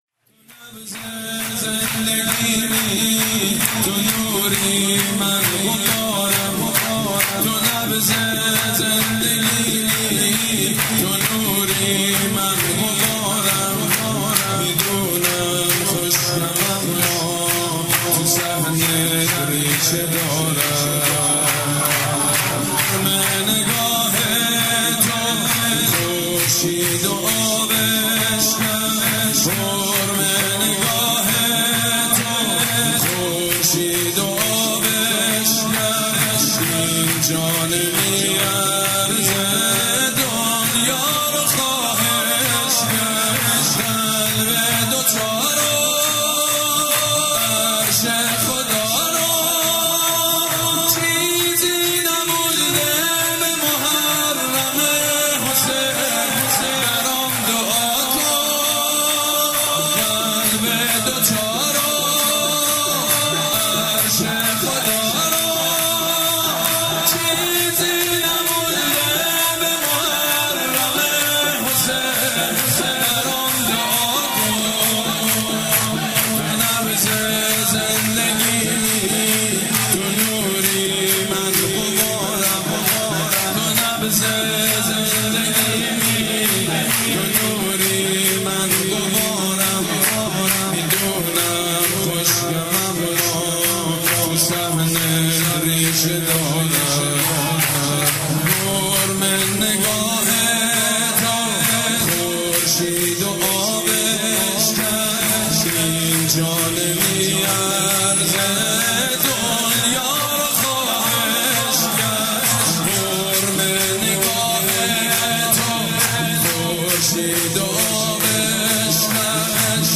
«میلاد امام رضا 1395» سرود: تو نبض زندگیمی